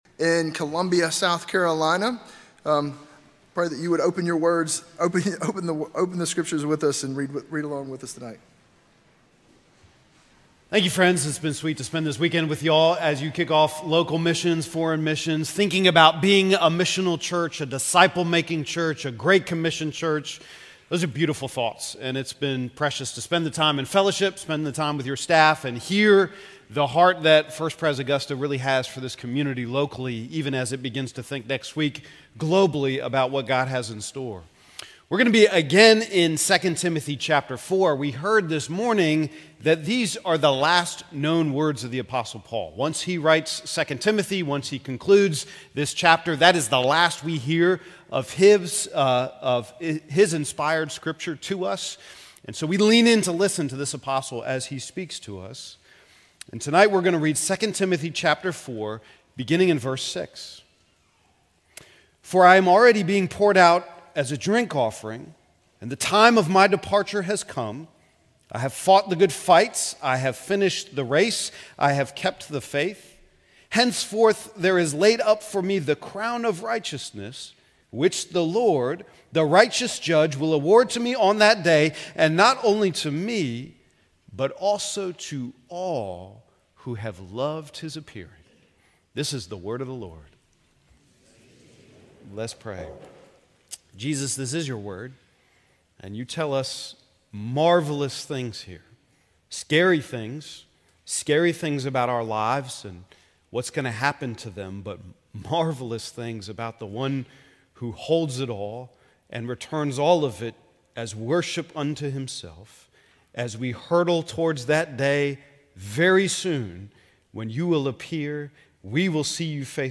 A sermon from the series "2026 Missions Conference."